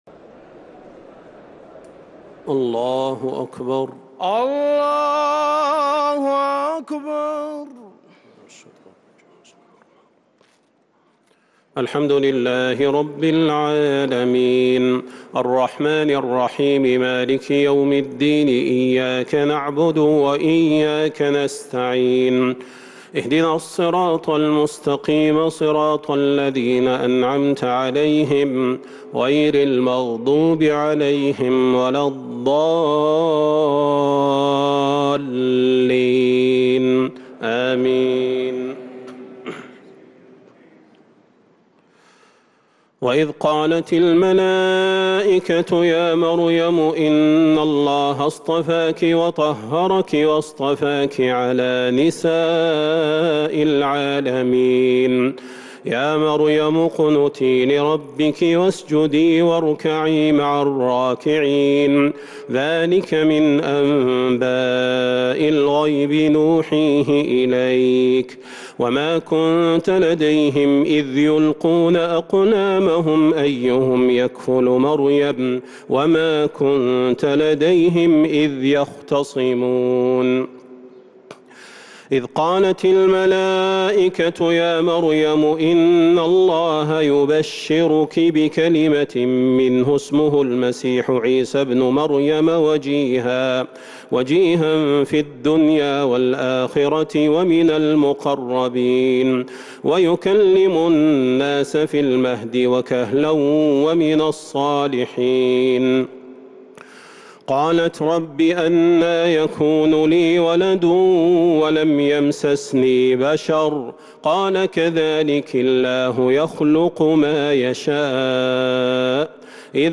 تراويح ليلة 4 رمضان 1443هـ من سورة آل عمران {42-92} Taraweeh 4st night Ramadan 1443H Surah Aal-i-Imraan > تراويح الحرم النبوي عام 1443 🕌 > التراويح - تلاوات الحرمين